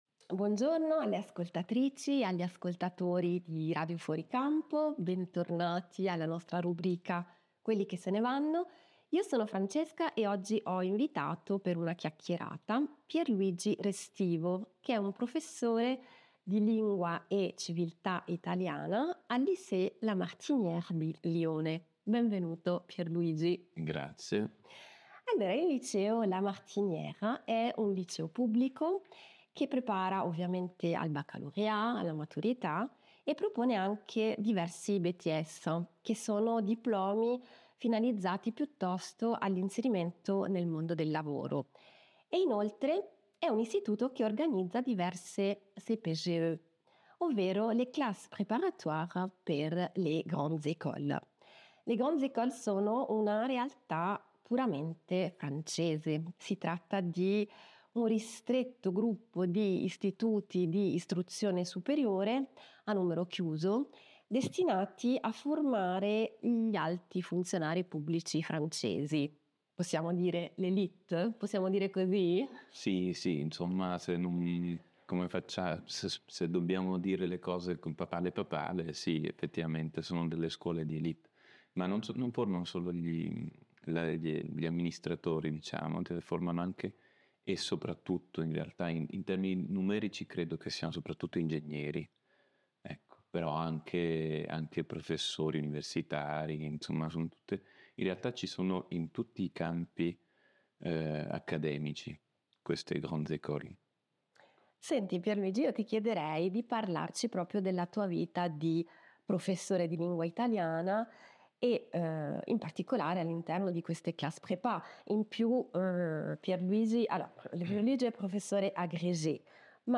Questo podcast, realizzato per la rubrica Quelli che se ne vanno, in onda sulla Webradio Radio Fuori Campo, descrive il sistema educativo francese, con particolare riferimento all’insegnamento superiore negli istituti più selettivi e prestigiosi, noti per formare l’élite nei campi dell’ingegneria, economia, amministrazione pubblica, scienze sociali e altre discipline.